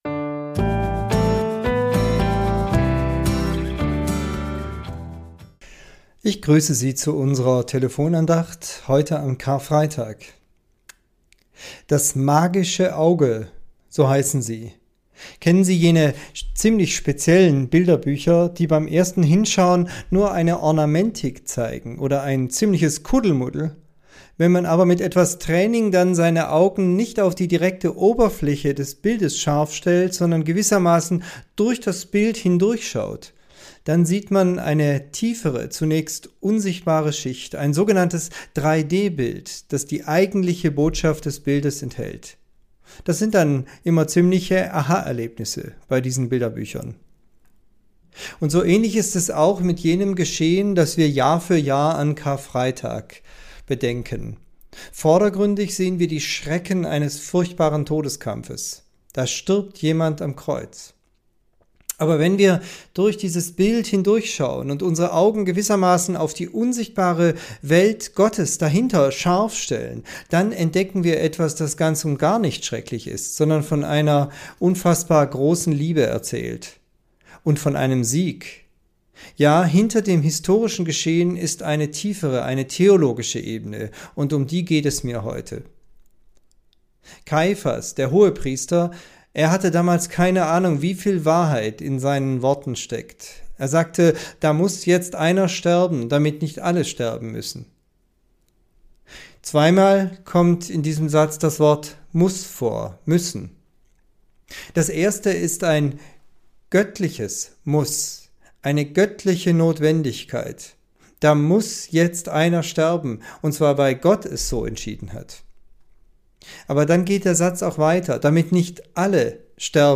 Tübinger Telefonandacht zur Tageslosung